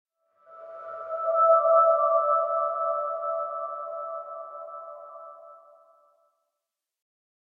Звуки пещер из Майнкрафт
Находясь в пещерах Майнкрафт можно услышать множество разных тревожных и порой даже пугающих звуков.